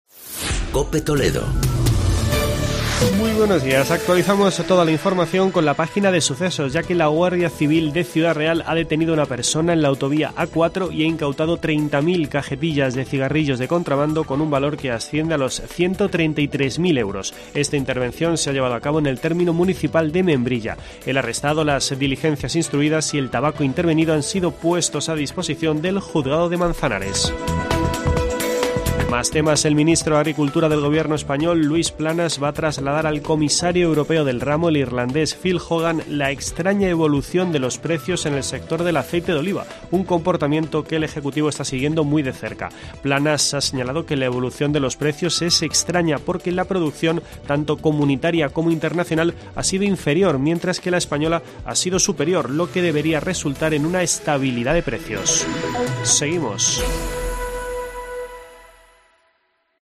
Boletín informativo de la Cadena COPE.